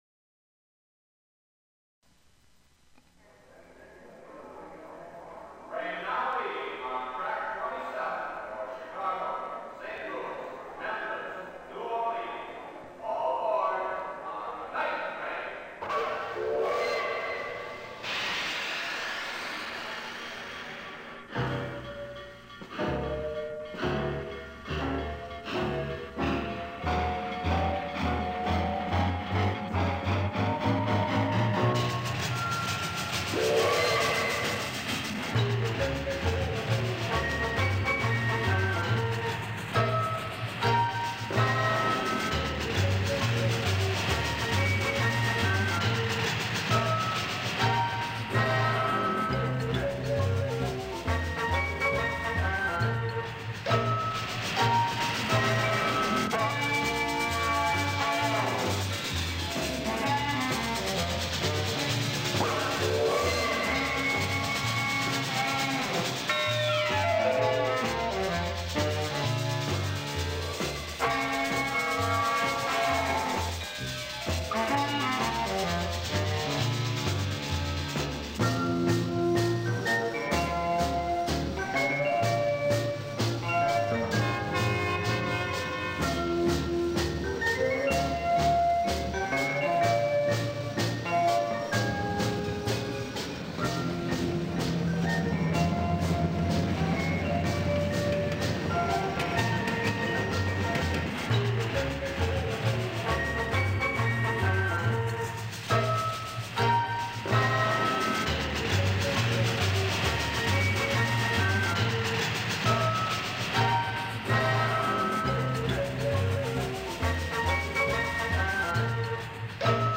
Through the medium of multitrack recording
the actual sound of the train